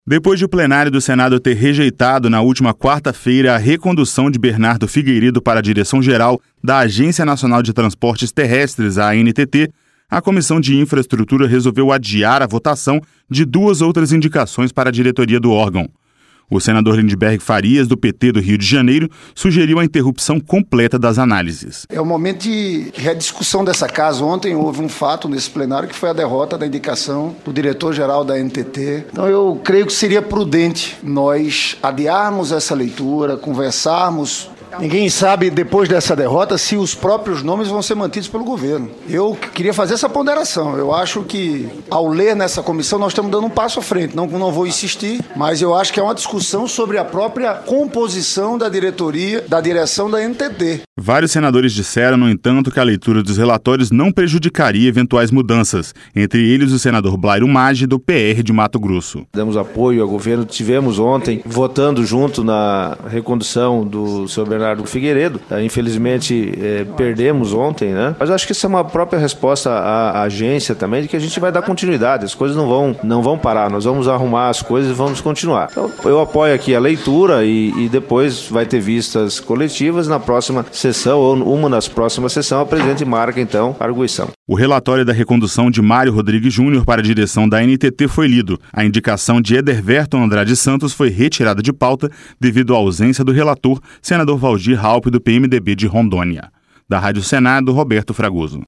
Senador Blairo Maggi
Senador Lindbergh Farias